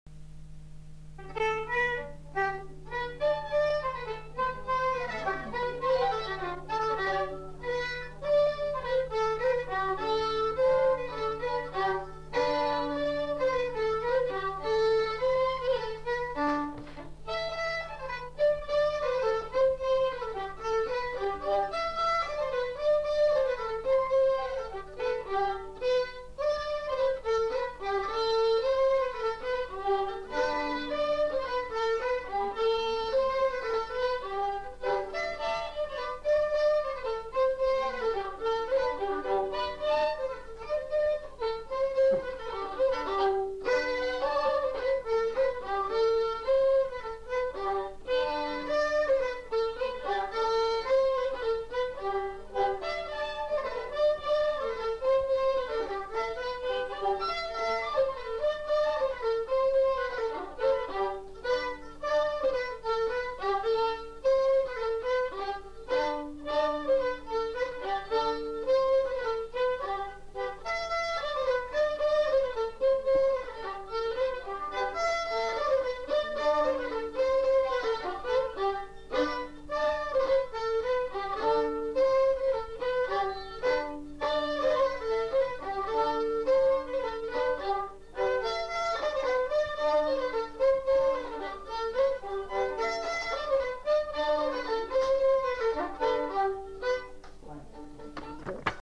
Lieu : Saint-Michel-de-Castelnau
Genre : morceau instrumental
Instrument de musique : violon
Danse : polka piquée
Notes consultables : 2 violons.